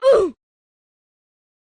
ROBLOX Oof Sound Effect.mp3